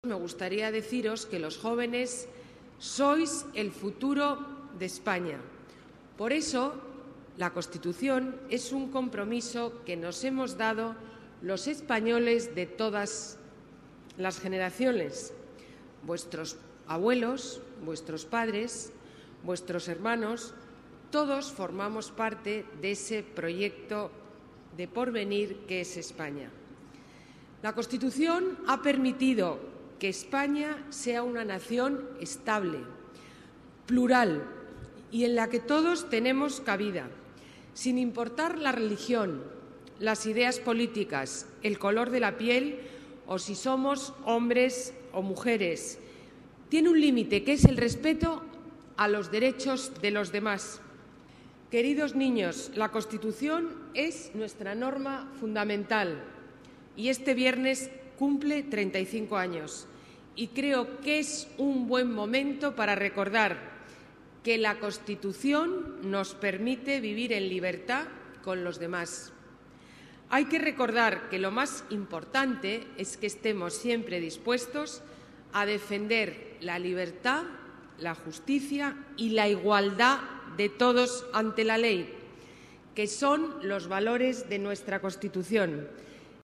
La alcaldesa celebra el 35 aniversario de la Carta Magna en un acto con niños en la plaza de Colón
Nueva ventana:Declaraciones alcaldesa Madrid, Ana Botella: homenaje 35 aniversario Constitución Española, futuro y compromiso